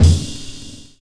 Kick (28).wav